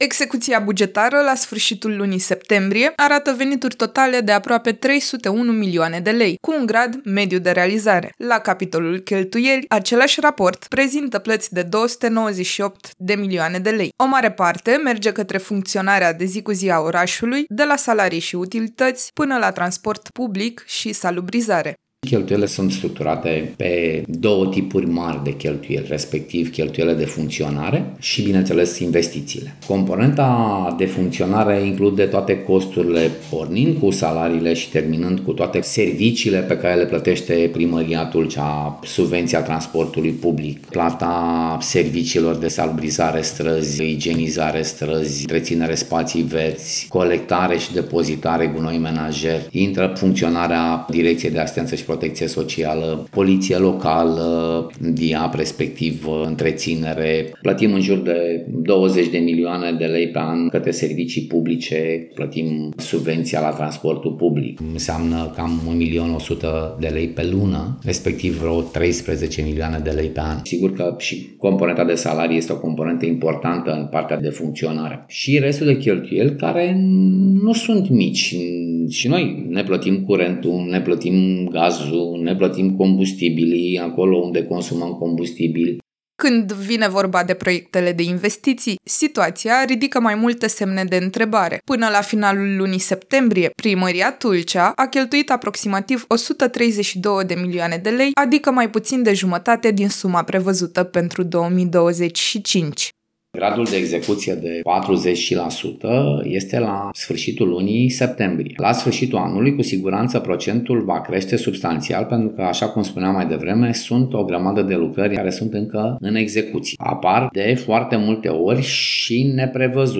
City manager Tulcea, Nicolae Bibu RC